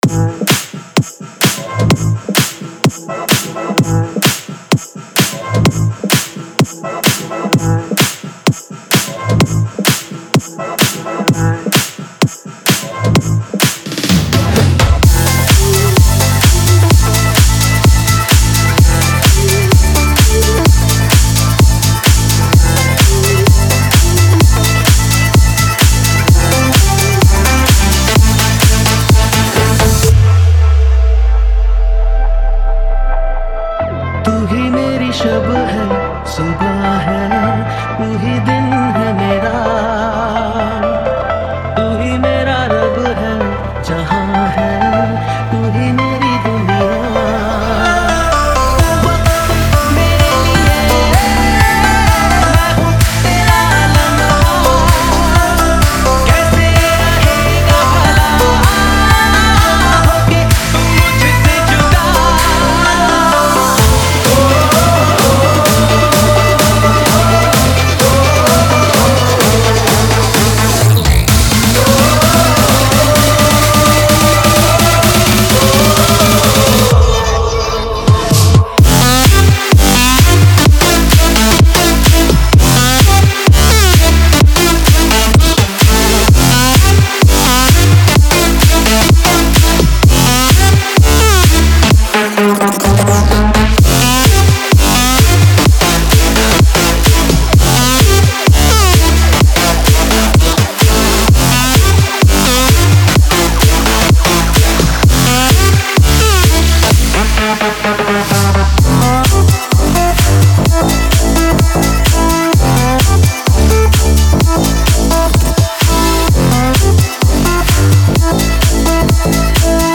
Category : Latest Dj Remix Song